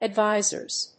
/æˈdvaɪzɝz(米国英語), æˈdvaɪzɜ:z(英国英語)/
フリガナアドバイザーズ